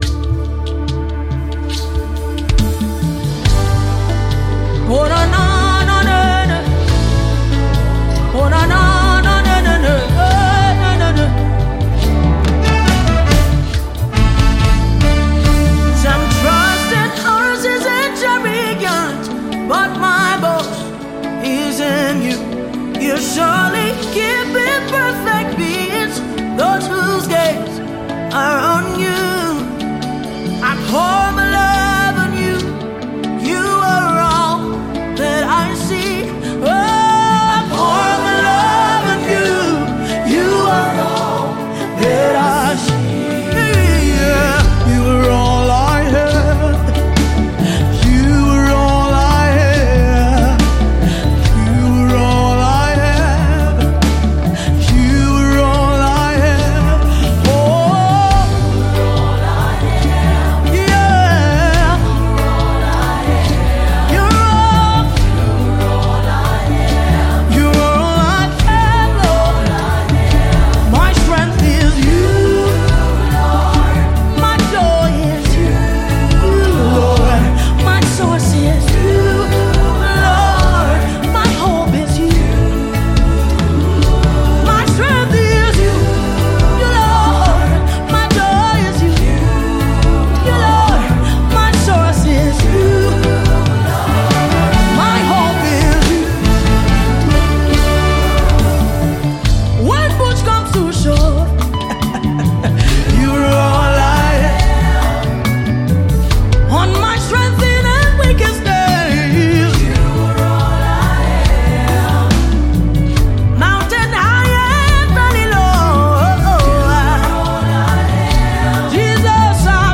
GospelMusic
Gospel